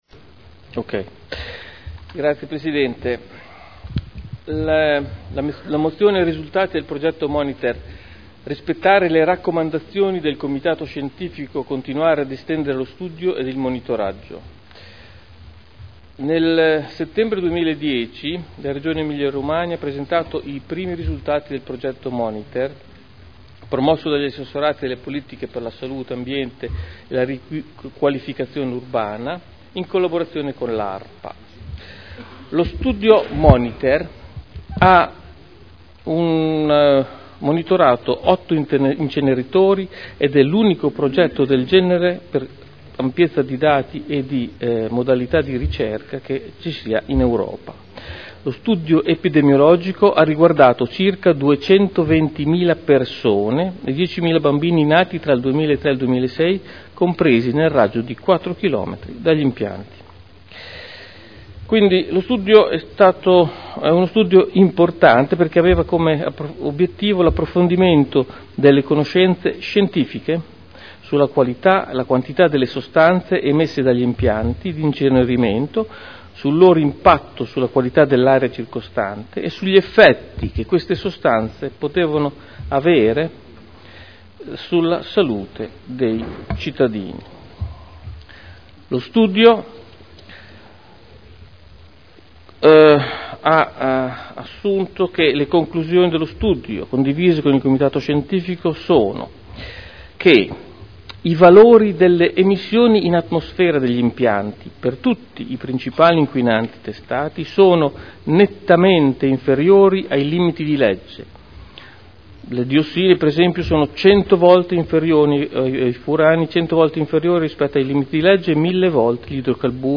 Luigi Alberto Pini — Sito Audio Consiglio Comunale